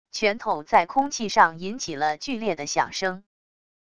拳头在空气上引起了剧烈的响声wav音频